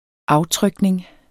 Udtale [ ˈɑwˌtʁœgneŋ ]